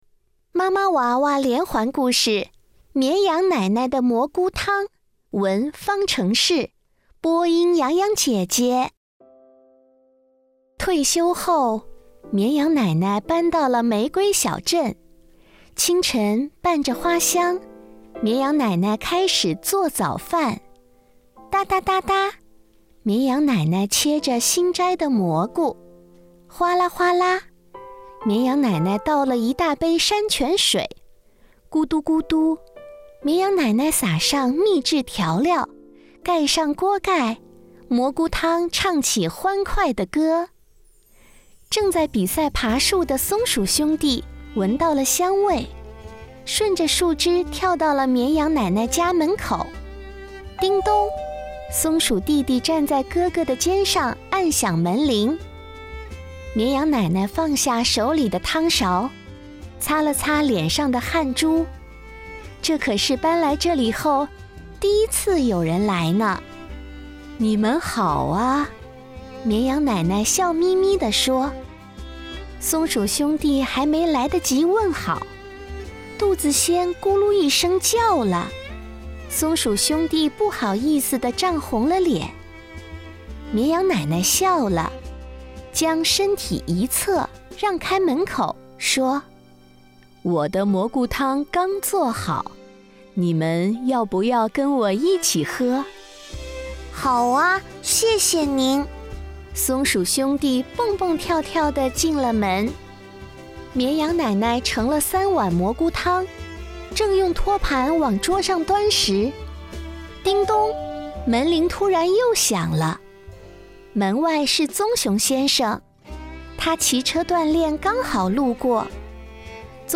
故事播讲